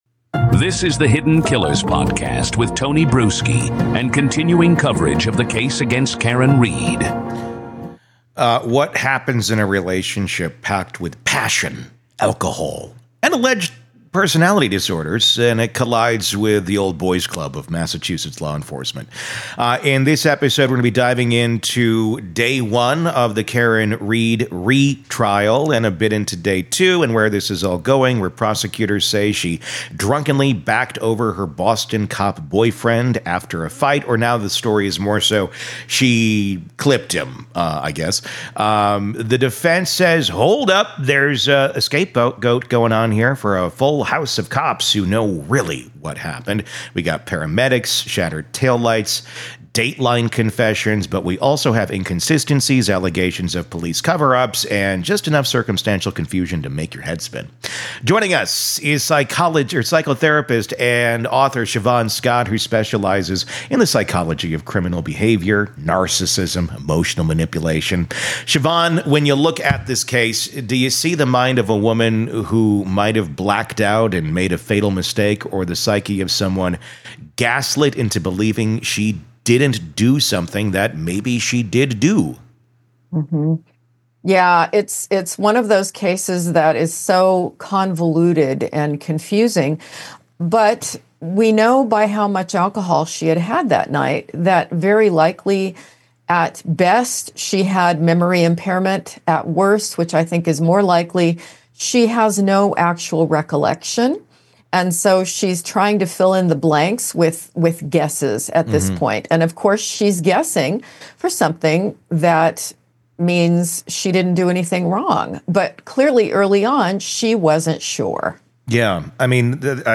True Crime Today | Daily True Crime News & Interviews / Karen Read Said 'I Hit Him' – But What Does That Really Mean?